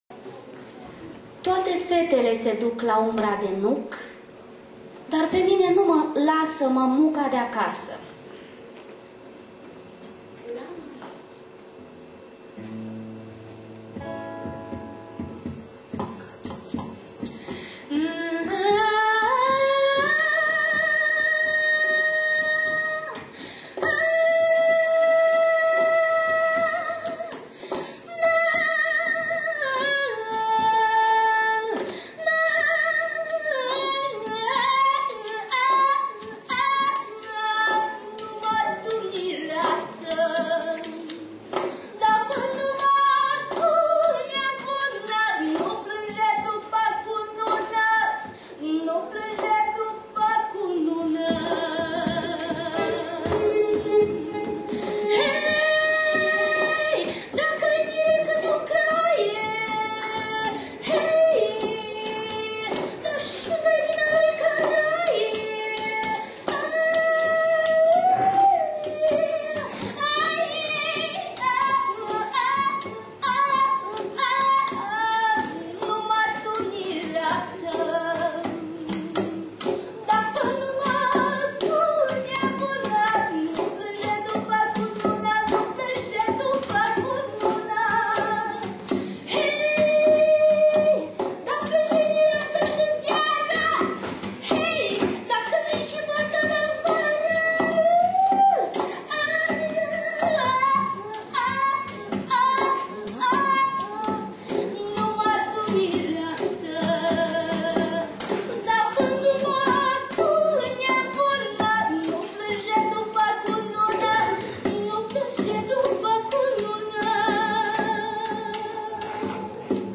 Recital
voce
esraj
ghitară